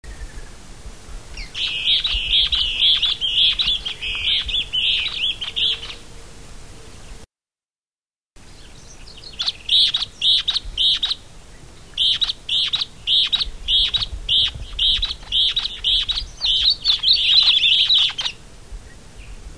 Duet recorded Aguara-Ñu, Mbaracayú Biosphere Reserve, Departamento Canindeyú